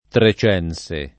trecense [ tre ©$ n S e ] etn. (di Troyes)